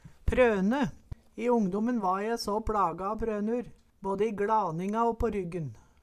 prøne - Numedalsmål (en-US)